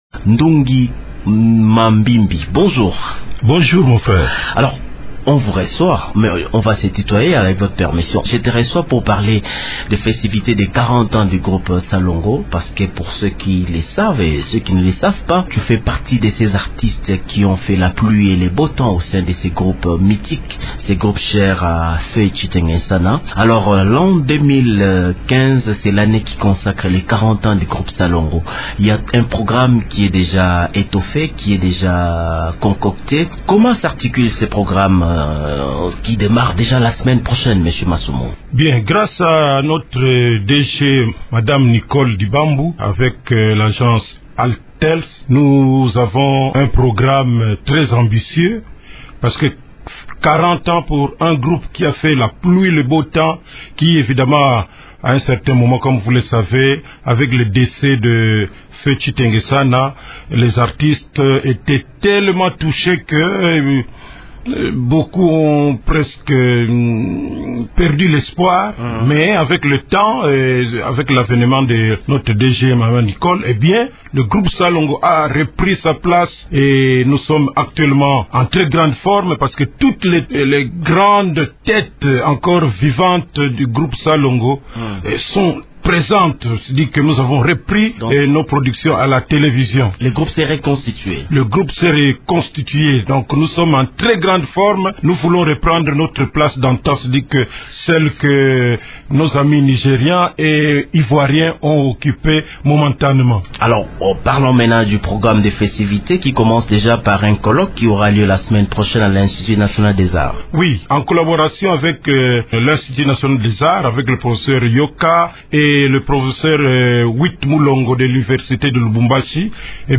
l’a annoncé au cours d’une interview accordée à Radio Okapi.